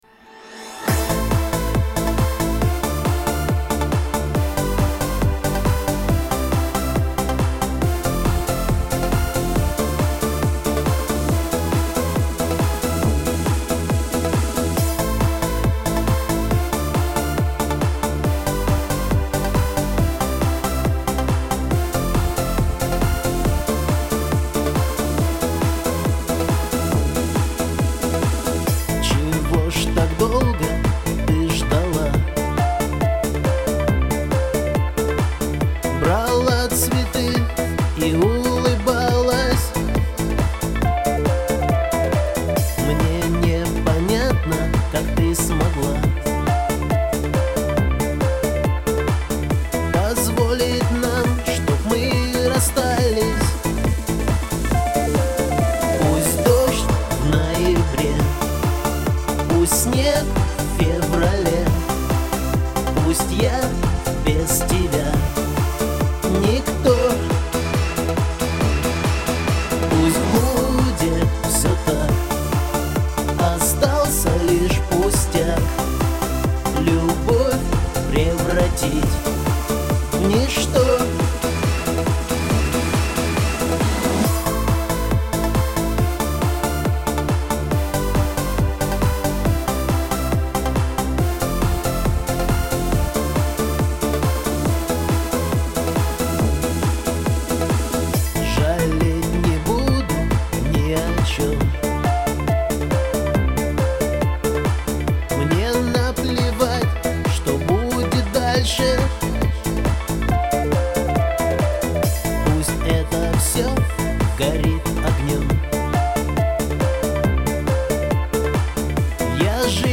Всі мінусовки жанру Disco
Плюсовий запис